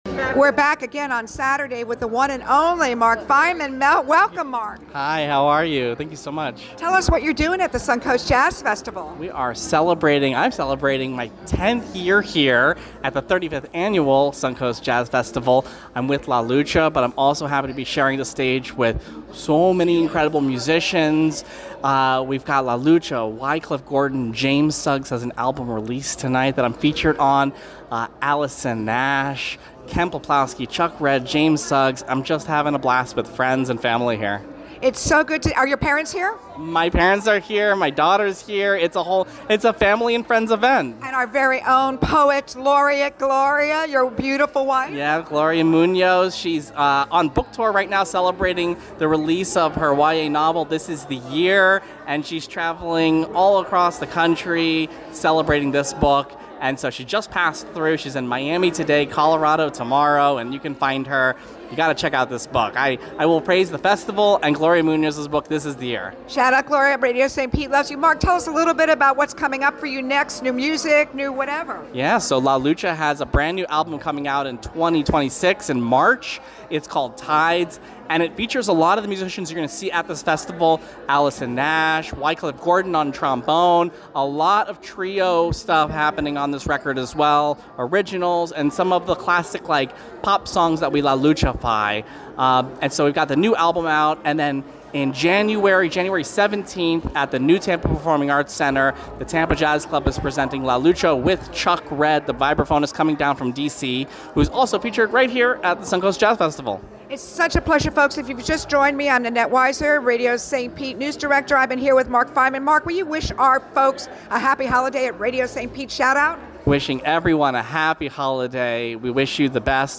LIVE from SUNCOAST JAZZ FESTIVAL